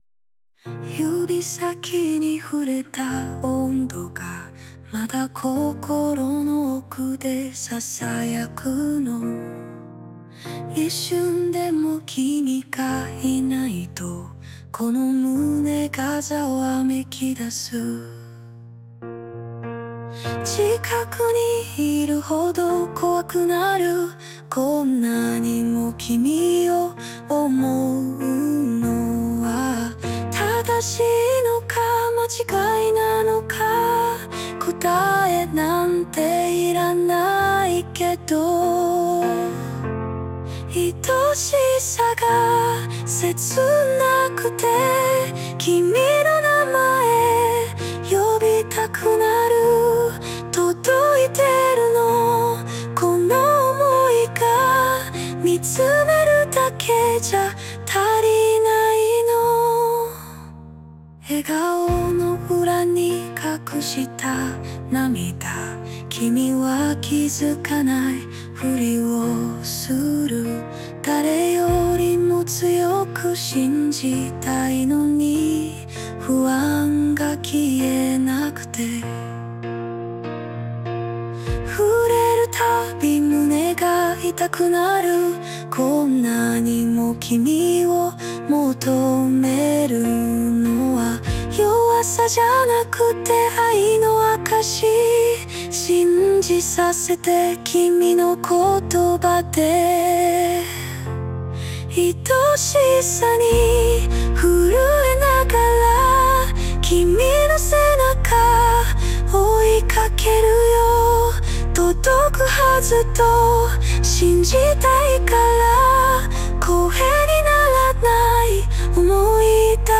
著作権フリーオリジナルBGMです。
女性ボーカル（邦楽・日本語）曲です。
好きすぎて切ない想いを歌うバラード曲ですが、個人的にかなりの名曲に仕上がっていると思います（笑）